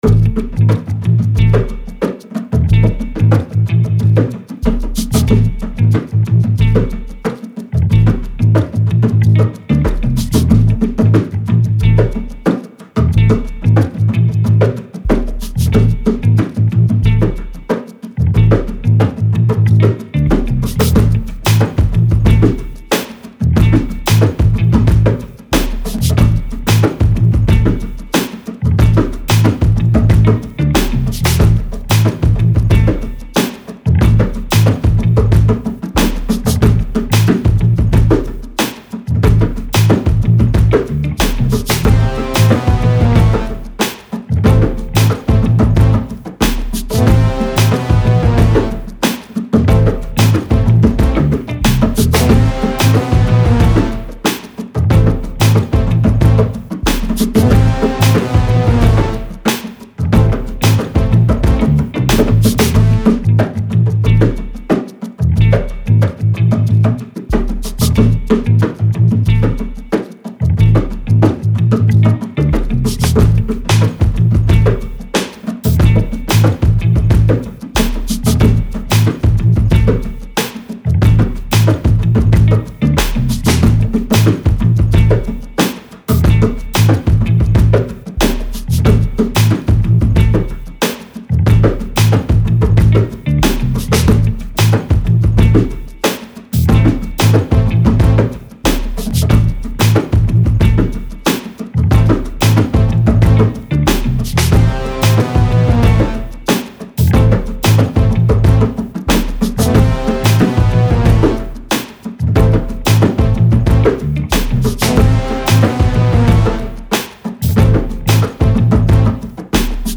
Laidback 70s retro groove with a funky bass theme.